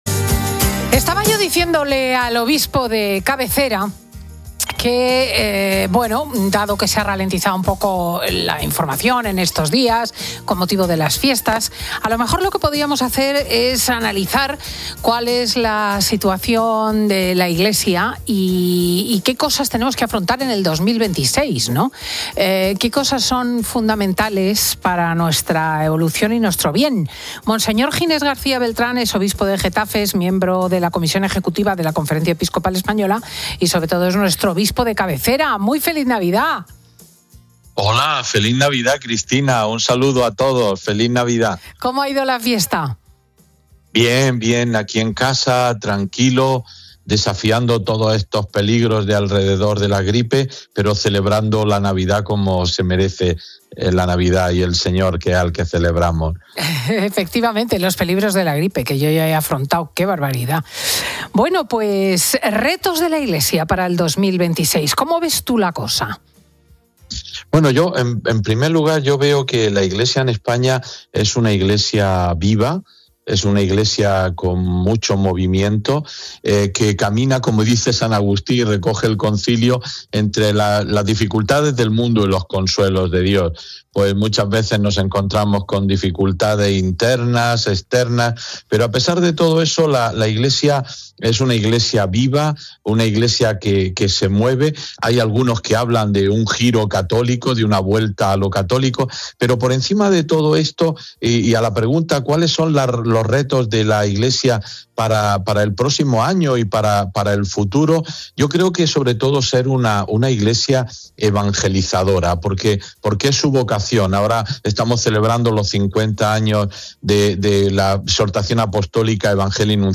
Monseñor Ginés García Beltrán analiza en COPE los grandes retos de la evangelización y el auge de un 'giro católico' que llena conciertos y retiros...